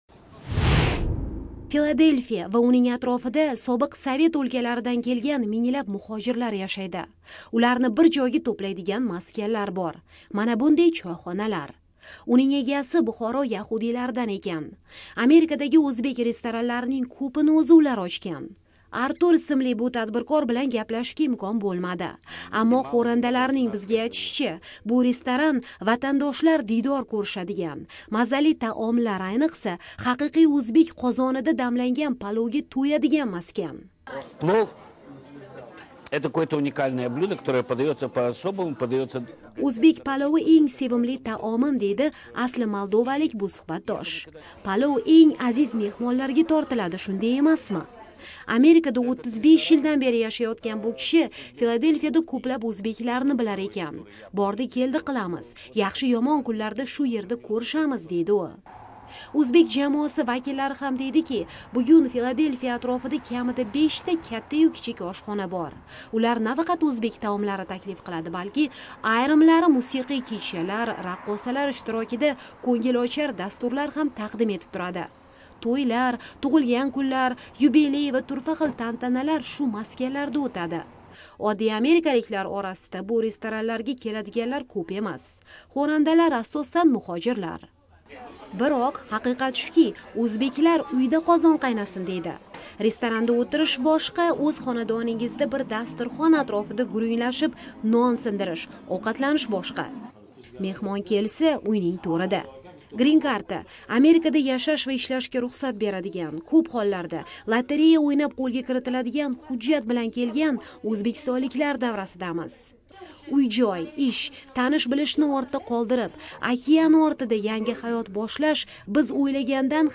Filadelfiya ko'chalarida